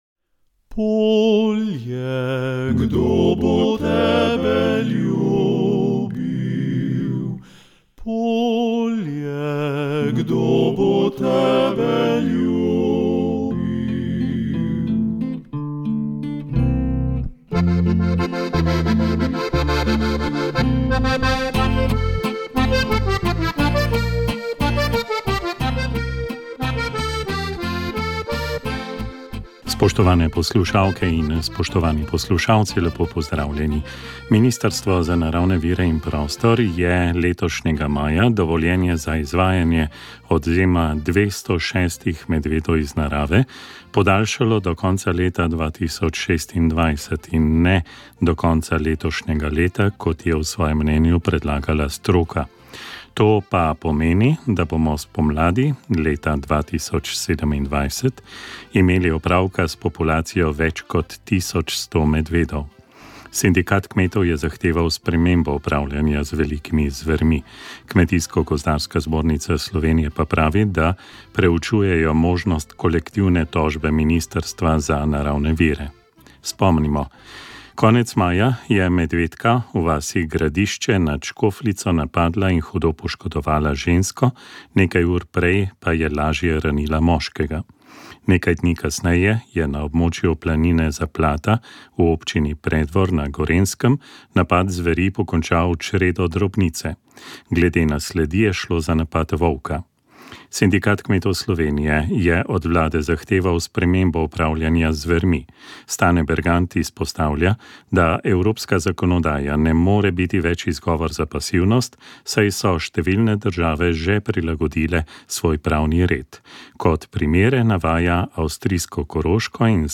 V večerni oddaji PRO, z začetkom ob 20.00, smo predstavili letošnjnje radijske dogodke, ki jo bo zaokrožila poskočna narodnozabavna glasba.